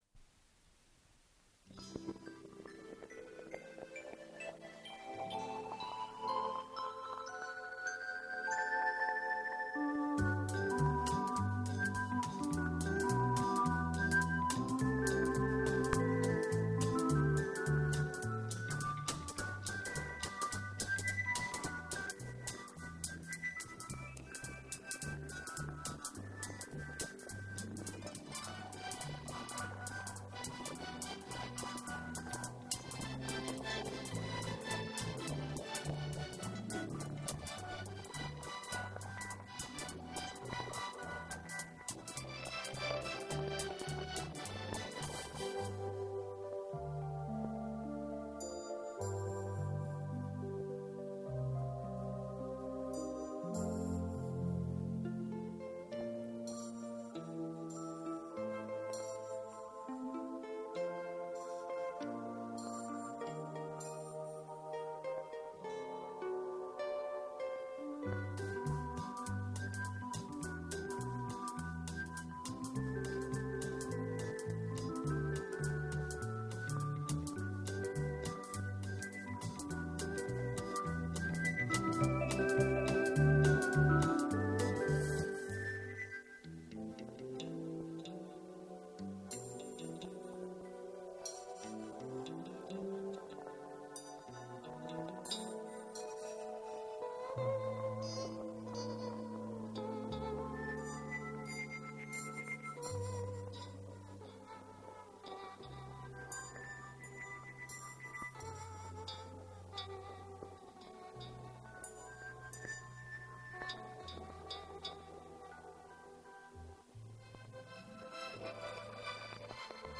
Дубль моно